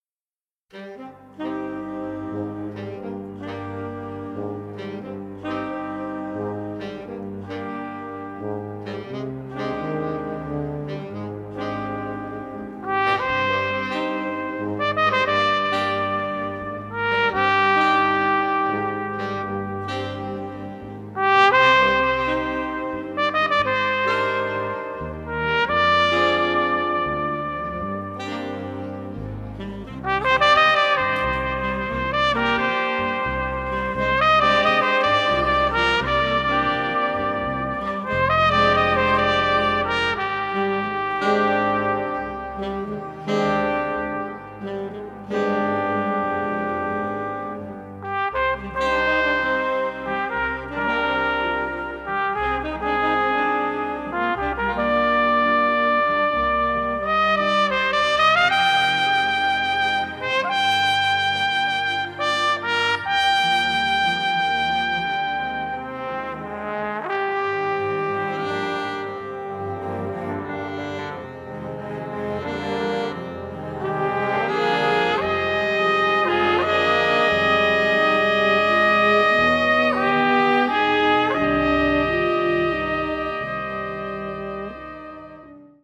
melancholic jazz music
saxophonists